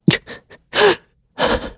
Siegfried-Vox_Sad_kr.wav